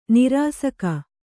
♪ nirāsaka